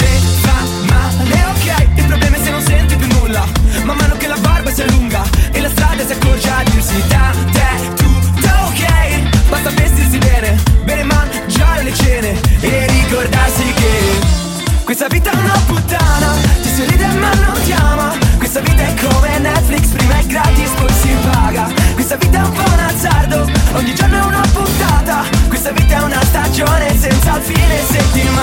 Genere: ,pop,trap,dance,rap,remix,italiana,hit